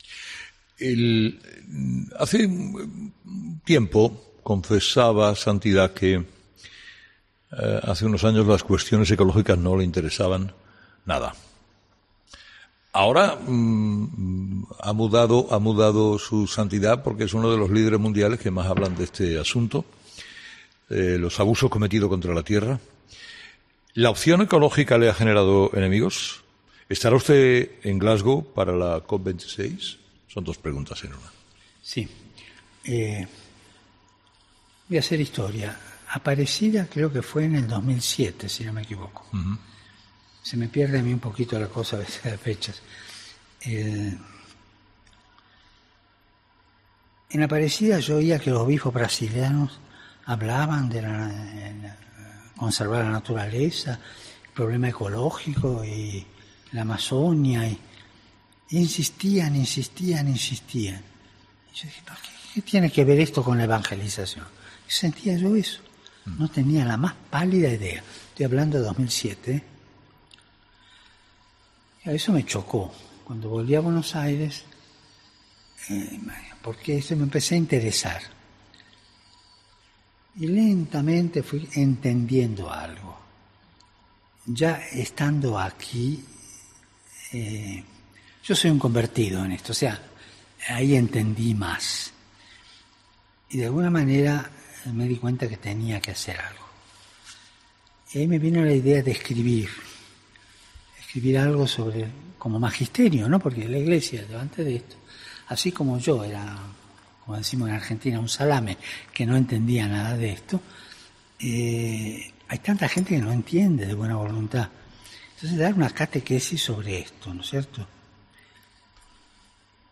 Lo confesaba el propio obispo de Roma a Carlos Herrera durante la entrevista que concedió el pasado 1 de septiembre al comunicador andaluz.